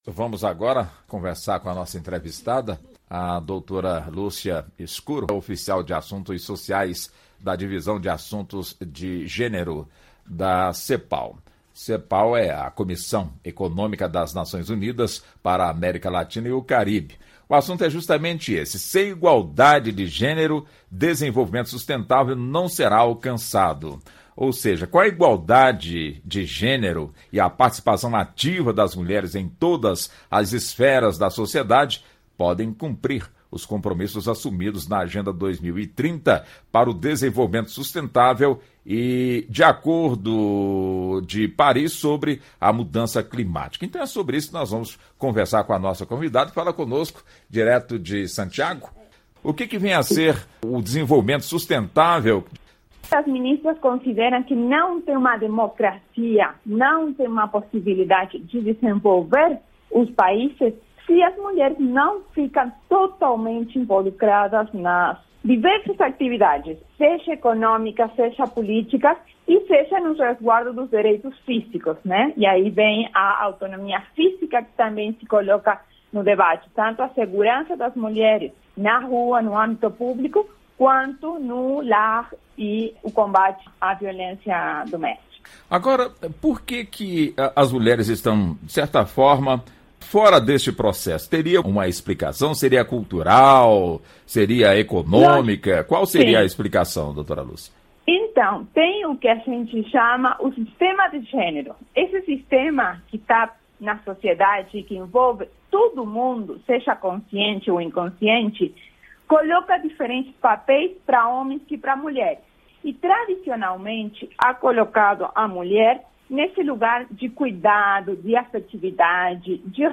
Entrevista: Representante da ONU pede maior inclusão das mulheres na economia e na política